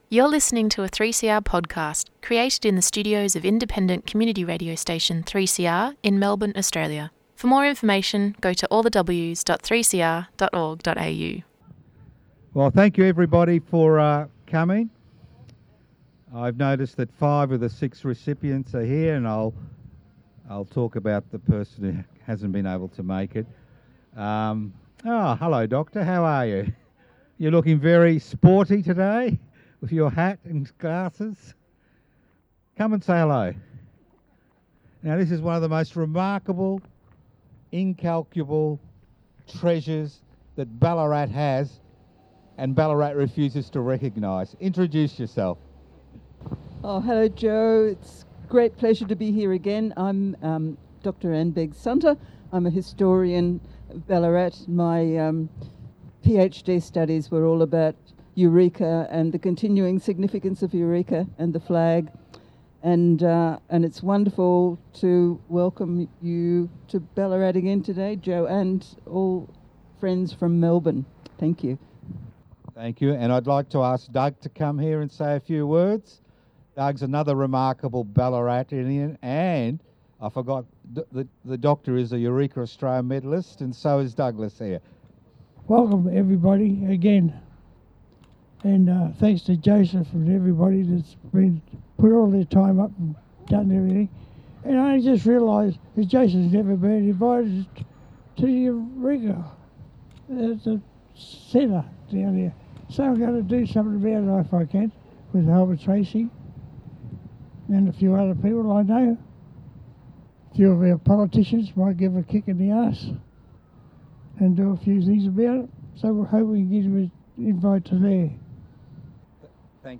These broadcasts are staged at live events around Melbourne or in the studios of 3CR in Fitzroy.
Eureka 2025: Eureka Australia Medal Presentations, Bakery Hill Ballarat